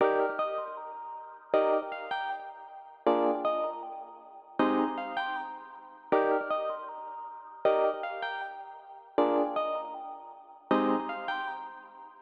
PBS (Wake Up 157Bpm).wav